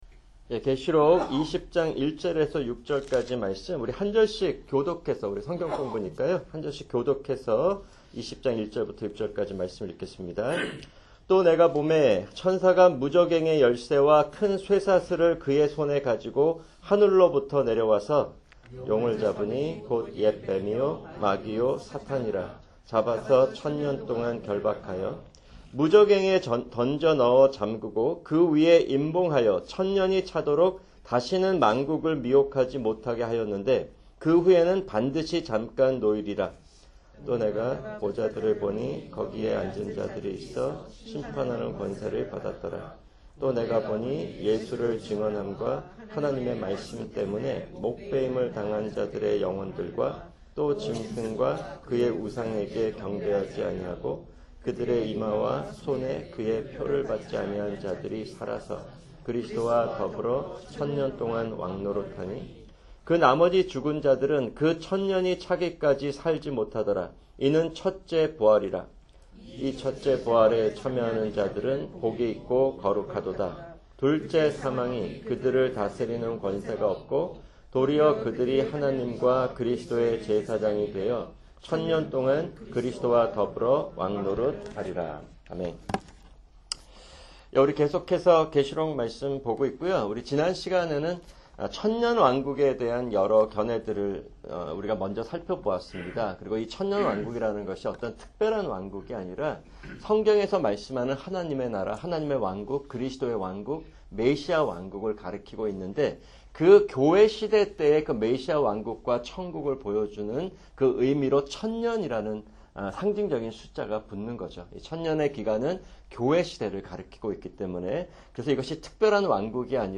[English Audio Translation] Galatians 3:15-29